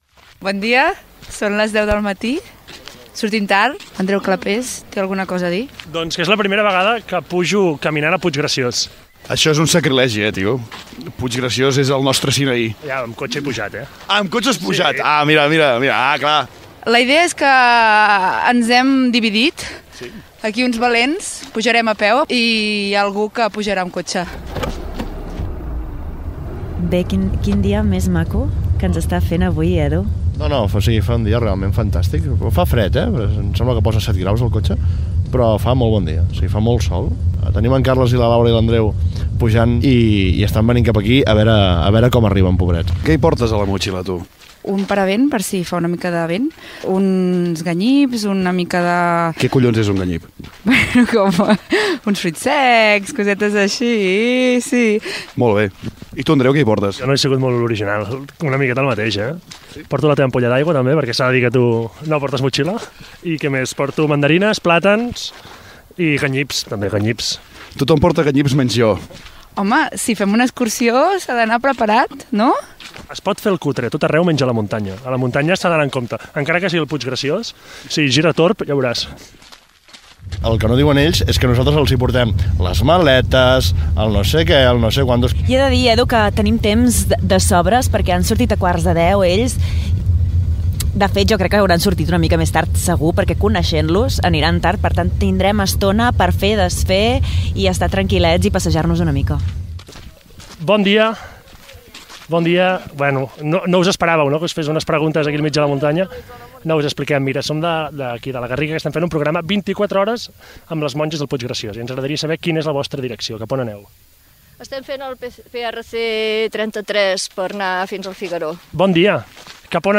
5cfaf73e384f8bfa49f148a85153c9c8214b8bb5.mp3 Títol Ràdio Silenci Emissora Ràdio Silenci Titularitat Pública municipal Nom programa Puiggraciós, amb Al Fons a l’Esquerra Descripció Inici de l'episodi 1 "Pujarem dalt del cim". Preparatius, aproximació, què esperen del cap de setmana els integrants de l'equip del programa "Al fons a l'esquerra", dades del lloc, arribada al monestir, dades de les monges de la comunitat, preparatius del dinar al monestir Gènere radiofònic Entreteniment